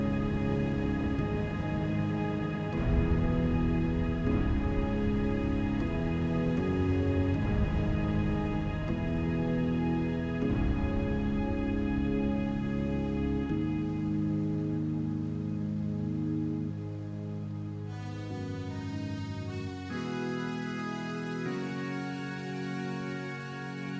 Long Version With No Lead Guitar Pop (1980s) 6:58 Buy £1.50